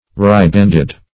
ribanded - definition of ribanded - synonyms, pronunciation, spelling from Free Dictionary Search Result for " ribanded" : The Collaborative International Dictionary of English v.0.48: Ribanded \Rib"and*ed\, a. Ribboned.